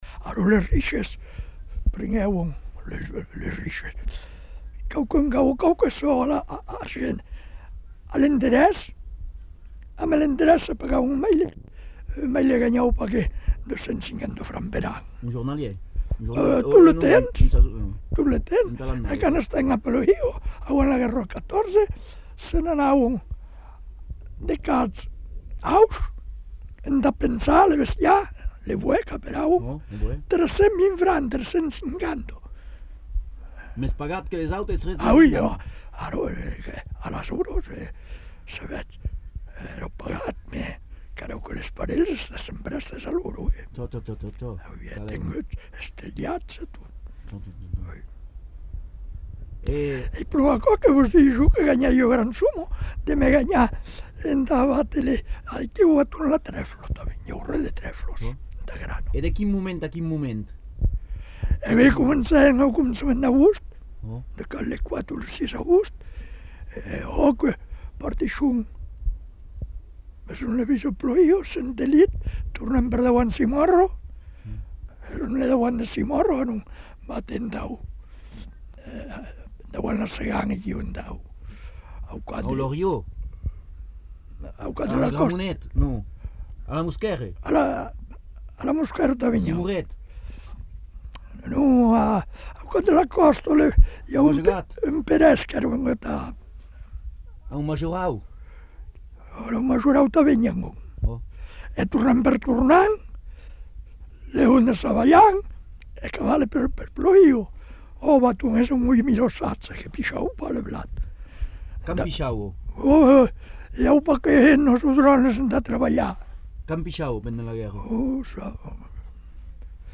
Lieu : Monblanc
Genre : témoignage thématique